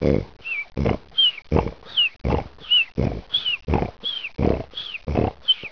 Whistle Snore Sound Effect Free Download
Whistle Snore